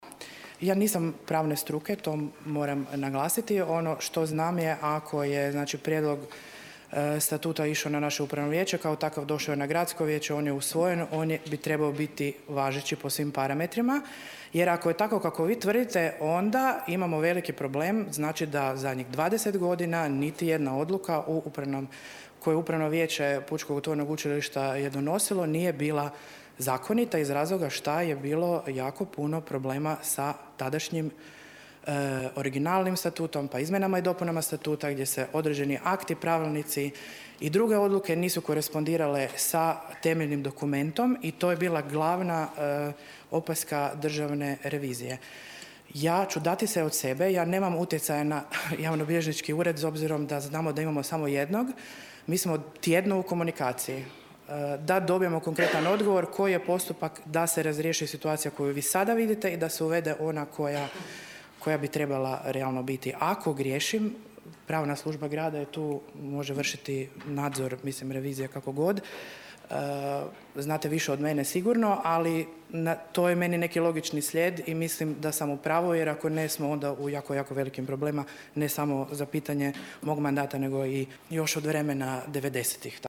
Gradsko vijeće Labina nije na današnjoj sjednici prihvatilo Izvještaj o radu Pučkog otvorenog učilišta za 2024. godinu.